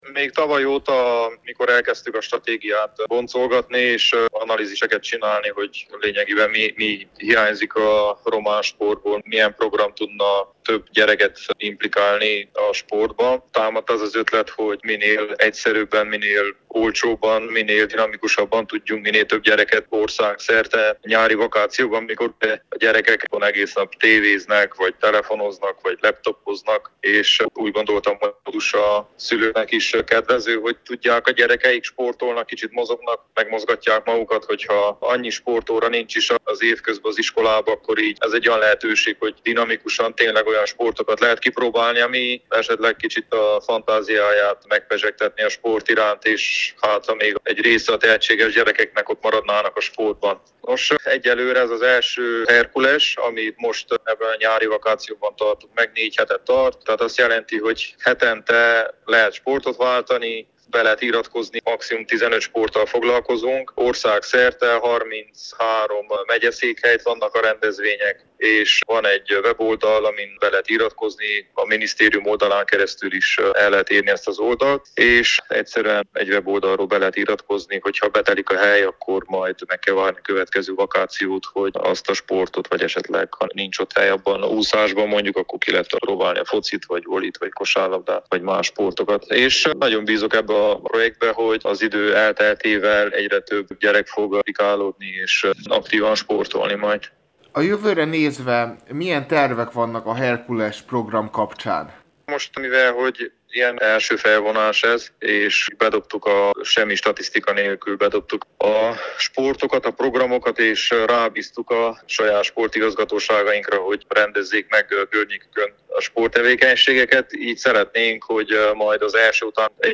Reménykednek abban, hogy a program hatására több fiatal kezd el aktívan sportolni, mondta Novák Eduárd sportminiszter